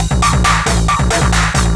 DS 136-BPM A2.wav